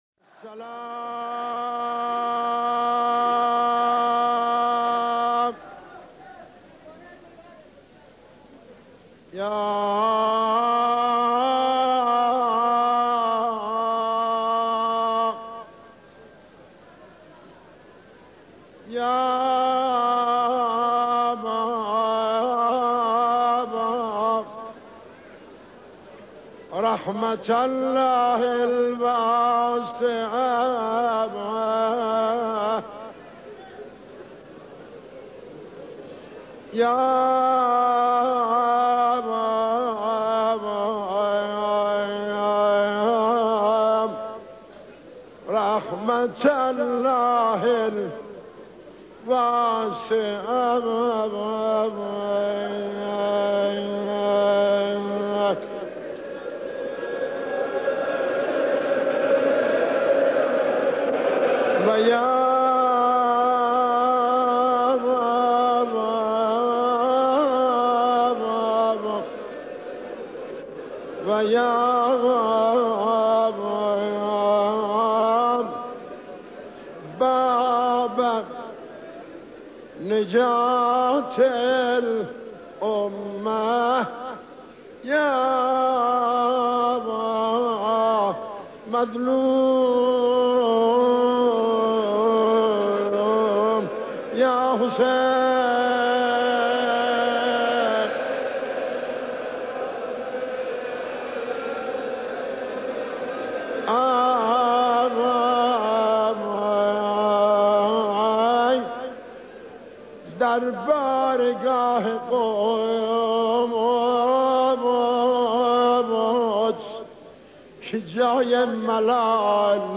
حیات اعلی :: دریافت مداحی 2 = سوگواره حسینی 9 محرم 1436